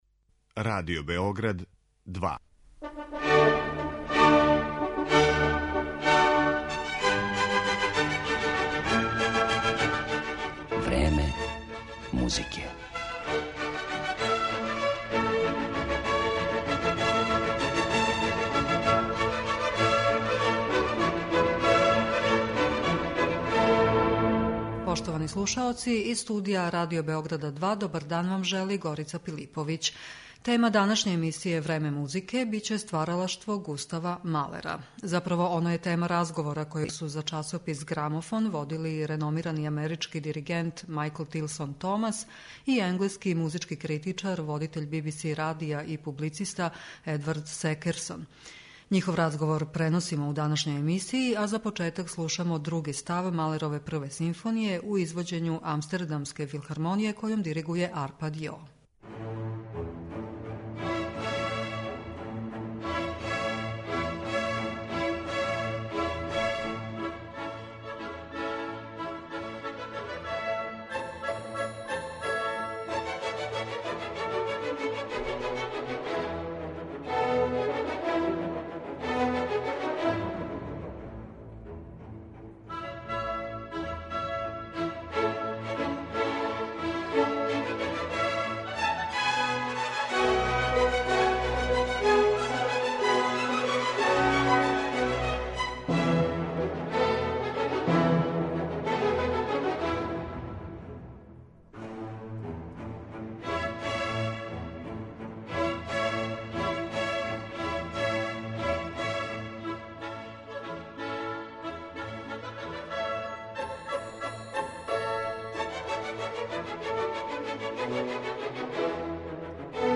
Њихов разговор преносимо у данашњој емисији